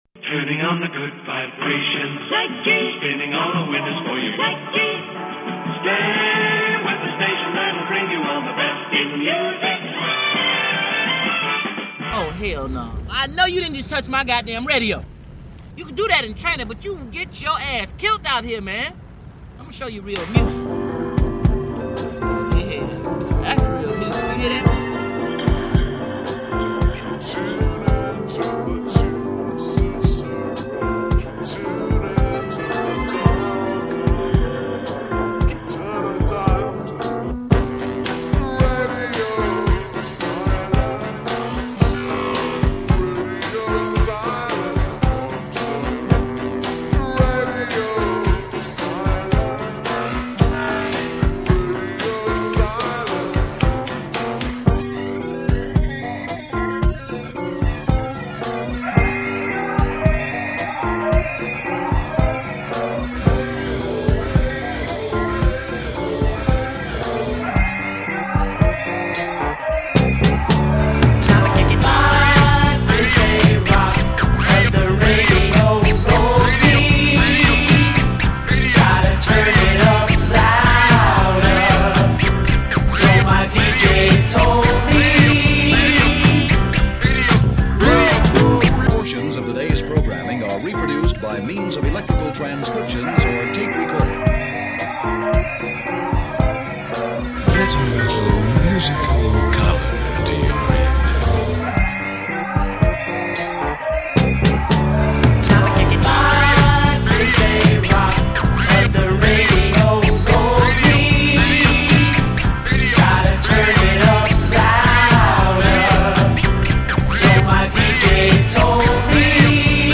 This is an excerpt from the Transmaniacon MC perfo...
Excerpt from Klip Show, made for first free103point9 Tune(In))) 030101 at NY Center for Media Arts.